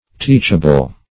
Teachable \Teach"a*ble\, a.